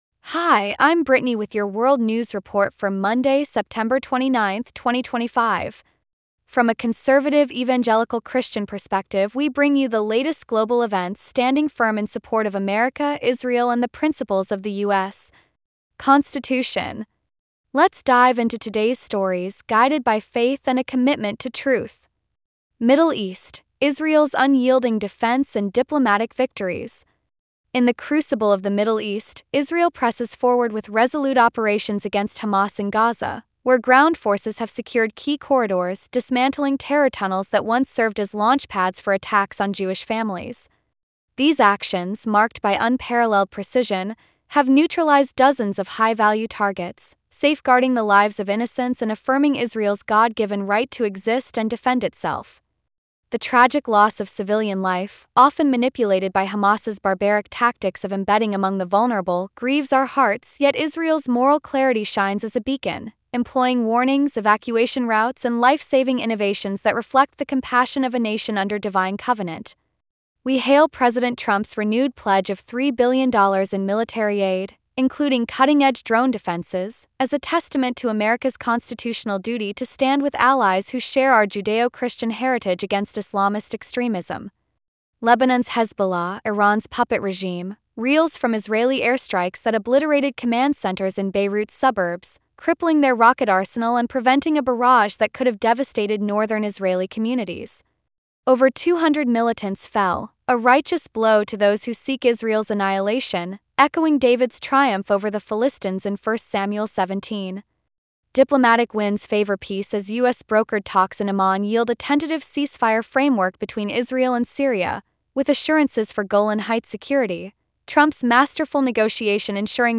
World News Report for Monday, September 29, 2025